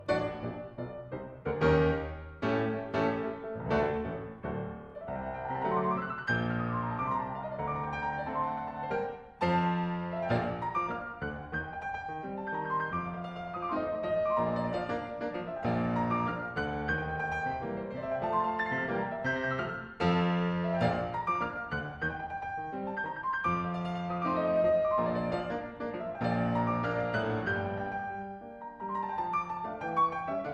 Une initiation aux instruments de l'orchestre et aux grands compositeurs classiques. Une sélection entraînante et un comédien familier des enfants guident le jeune public à travers les styles et les époques.
mozard : Sonate pour violon et piano, KV.376@964 johann strauss (fils) : Marche des cavaliers@964 ivaldi : L'ete (Les 4 saisons)@964 manuell saumell : Contredanse@964 bizet : Prelude de l'acte N1 (Carmen)@964